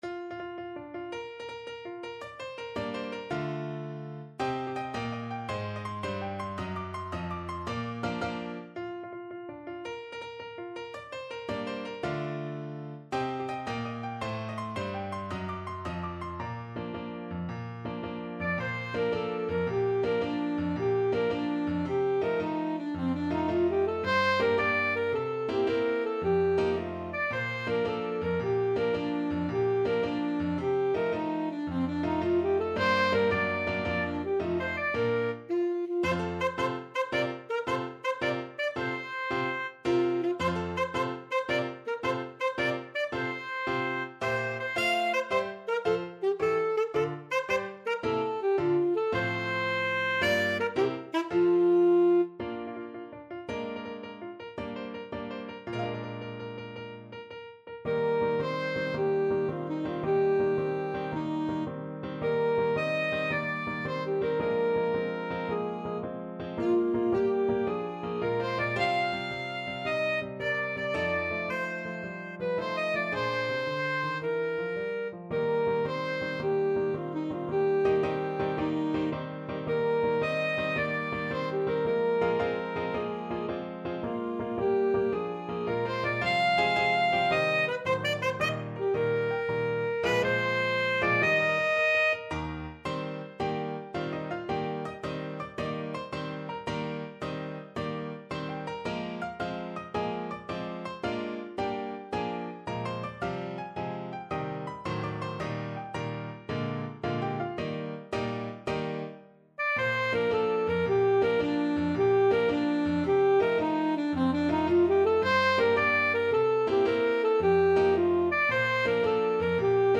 Alto Saxophone
March .=c.110
6/8 (View more 6/8 Music)
C5-F6
Classical (View more Classical Saxophone Music)